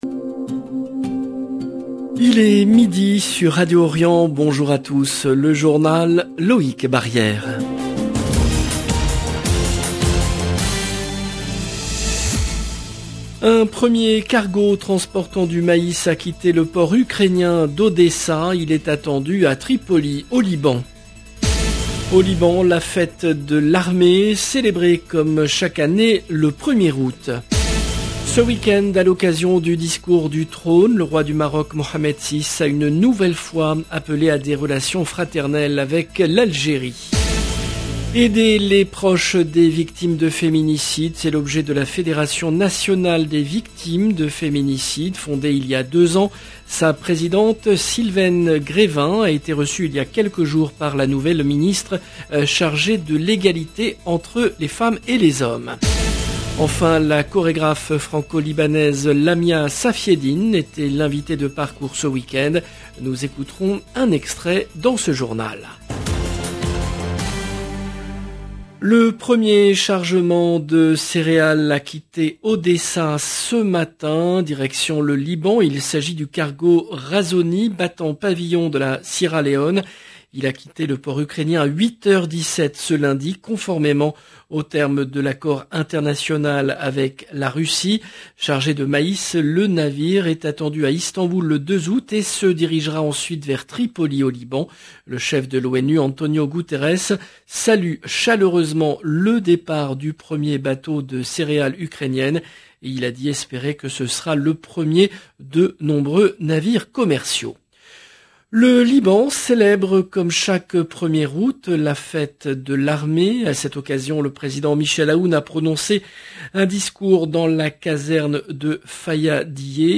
LE JOURNAL EN LANGUE FRANCAISE DE MIDI DU 1/08/22